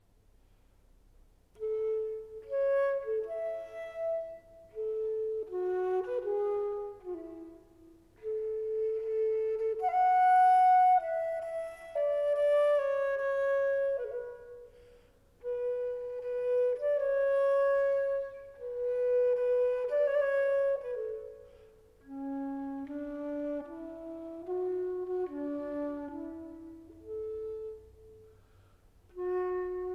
Classical Vocal
Жанр: Классика